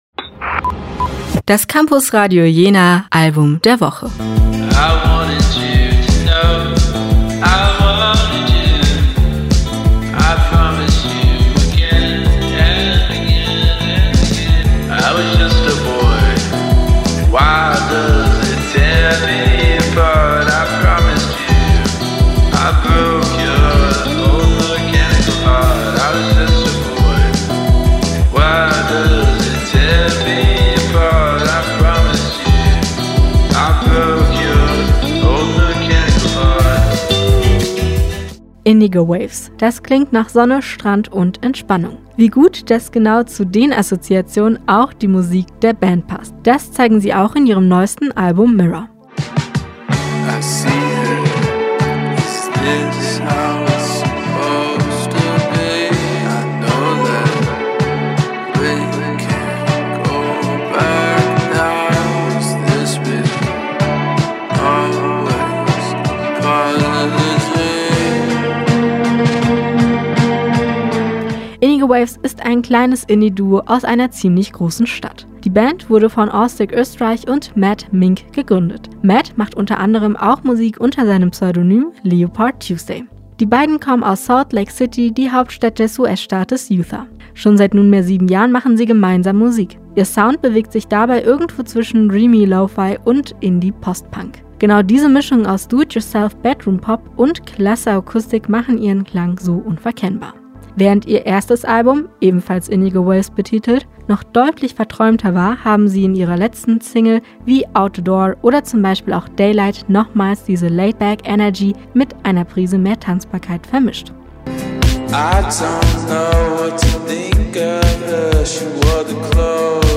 reflektiert, eindringlich und nachklingend